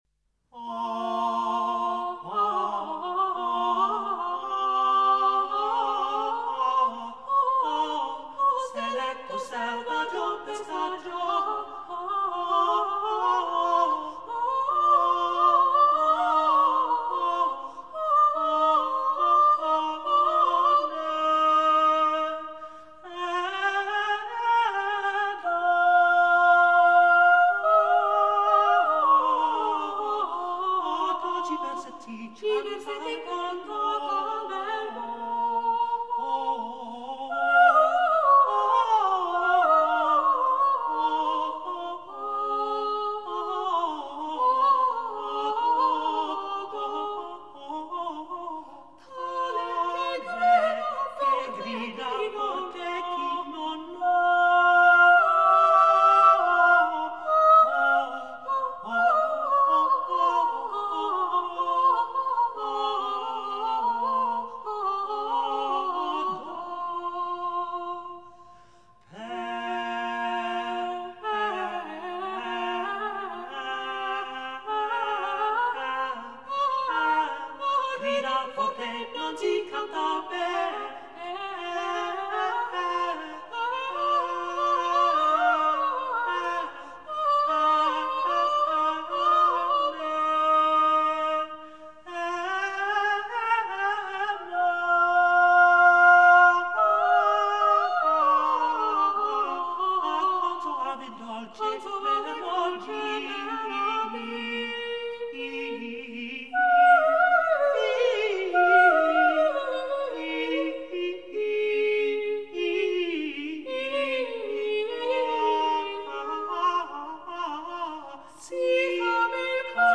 Oselletto selvagio - Madrigale